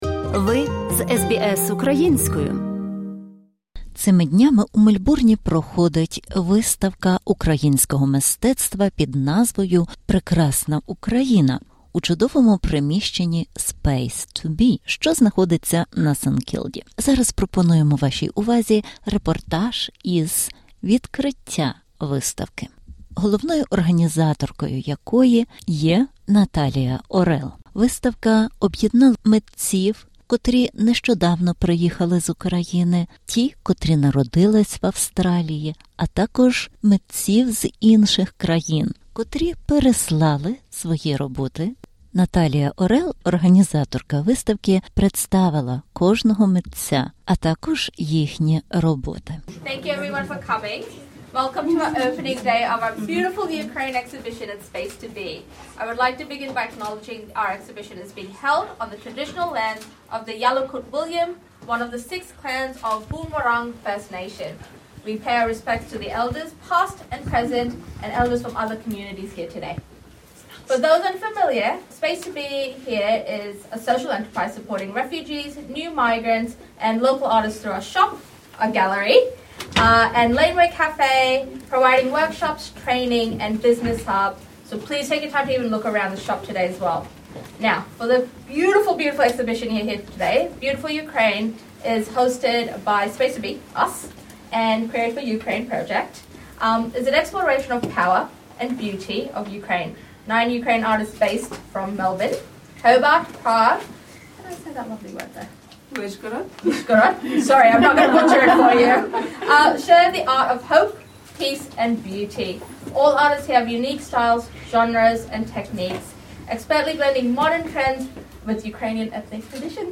Репортаж.